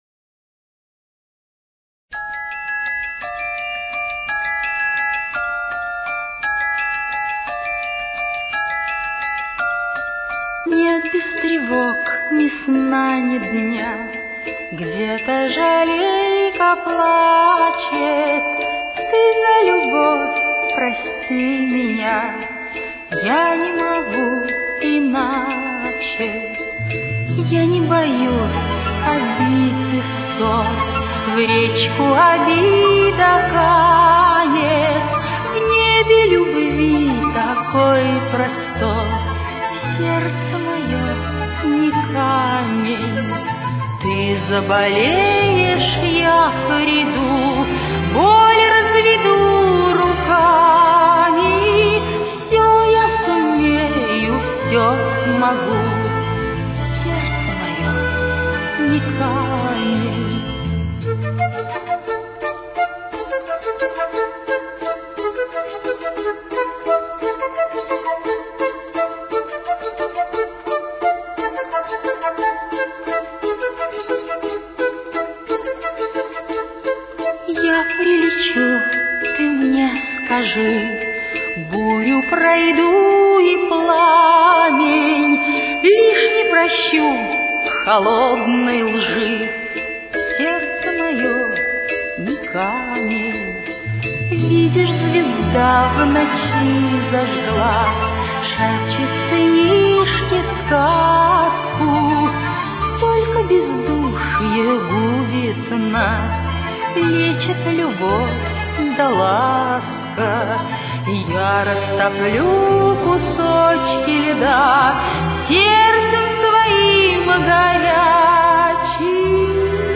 Темп: 175.